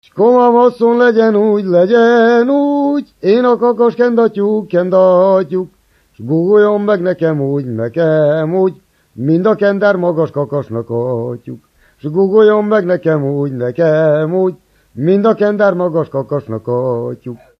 Erdély - Alsó-Fehér vm. - Hari
ének
Műfaj: Szökő
Stílus: 2. Ereszkedő dúr dallamok
Kadencia: 9 (5) 5 1